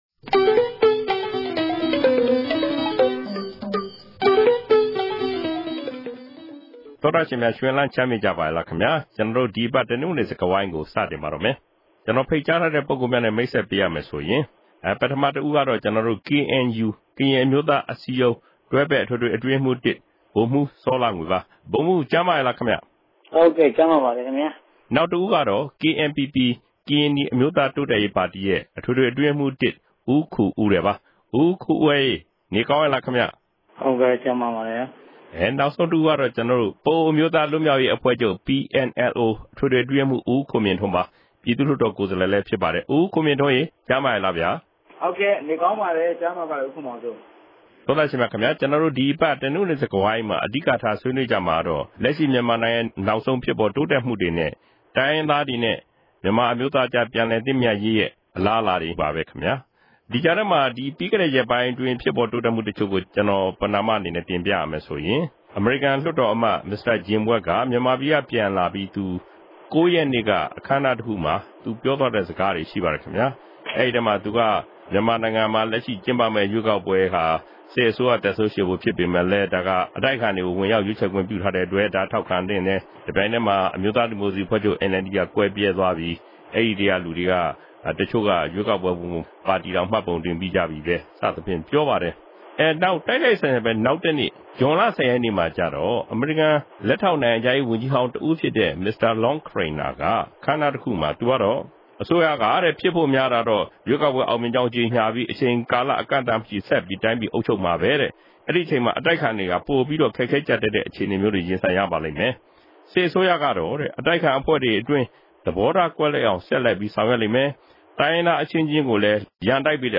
တိုင်းရင်းသားခေၝင်းဆောင် သုံးဦးက ပၝဝင် ဆြေးေိံြးထားပၝတယ်။